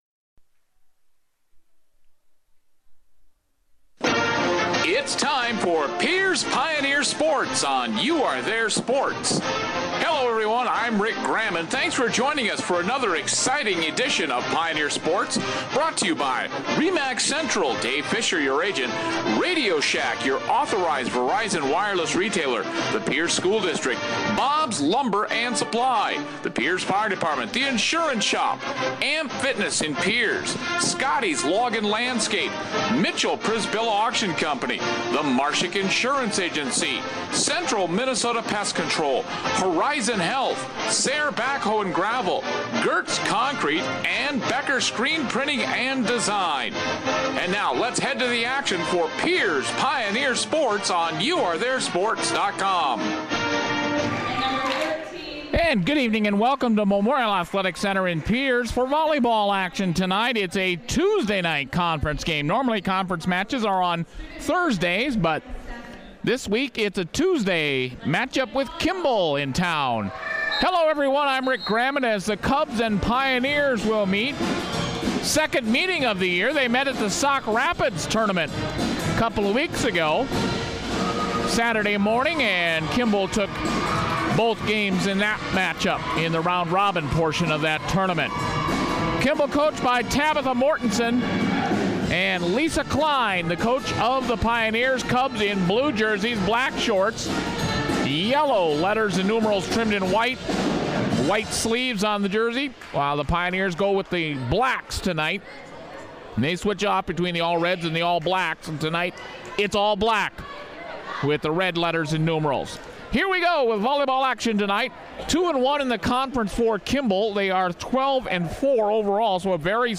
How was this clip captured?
9/29/15 Pierz Pioneers vs Kimball Cubs Volleyball